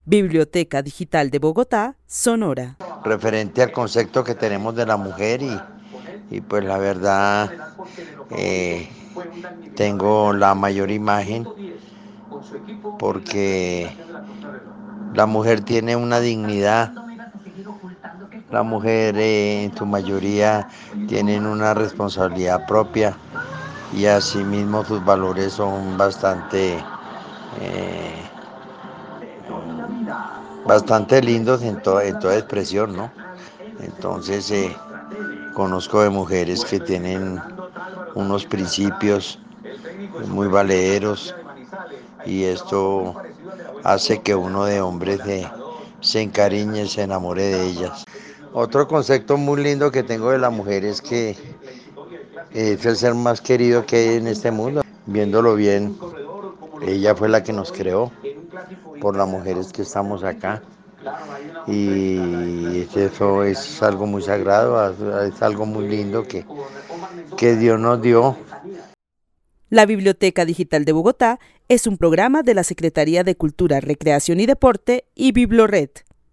Narración oral de un hombre que vive en la ciudad de Bogotá y que admira la dignidad que tienen las mujeres, la responsabilidad propia y sus lindos valores. Resalta que las mujeres con principios valederos, hacen que los hombres se enamoren de ellas.
El testimonio fue recolectado en el marco del laboratorio de co-creación "Postales sonoras: mujeres escuchando mujeres" de la línea Cultura Digital e Innovación de la Red Distrital de Bibliotecas Públicas de Bogotá - BibloRed.